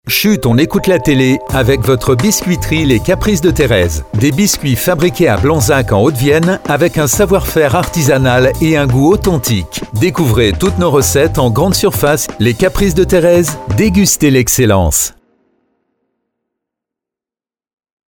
et voici le spot de notre partenaire